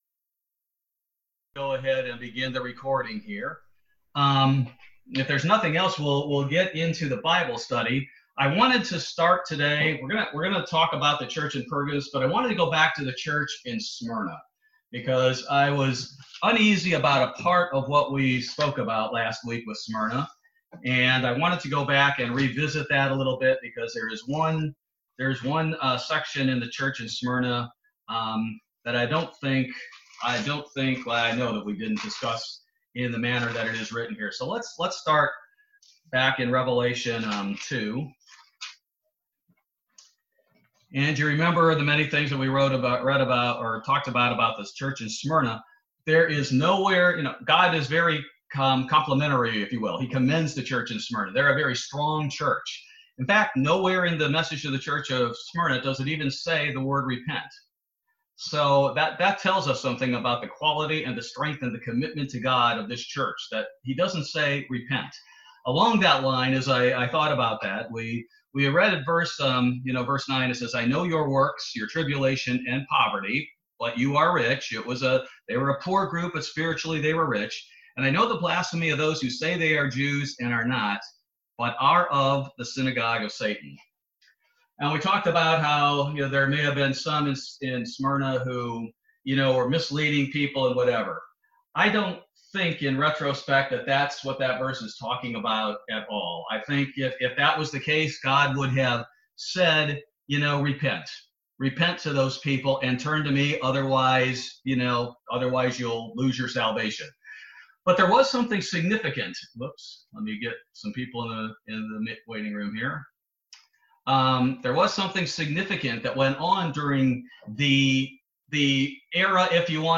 Online Bible Study continuing the Book of Revelation